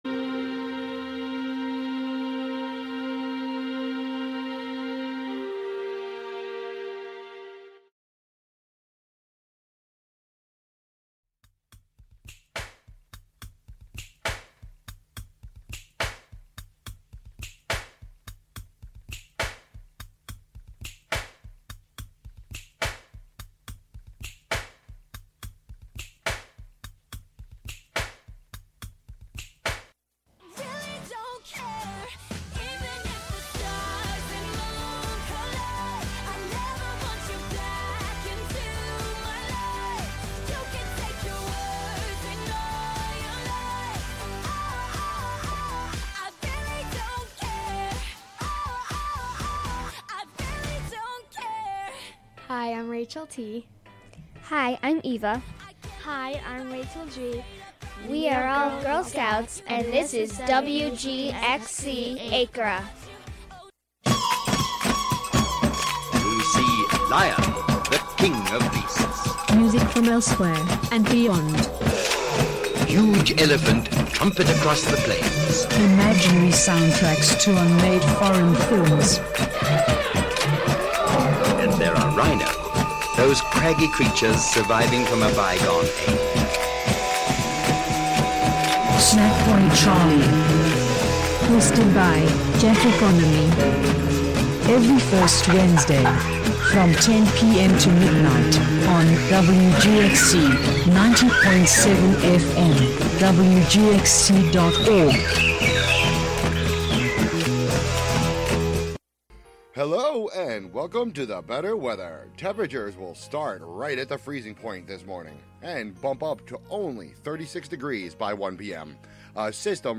Featuring Gospel, Inspirational, Soul, R&B, country, Christian jazz, hip hop, rap, and praise and worship music of our time and yesteryear; interwoven with talk, interviews and spiritual social commentary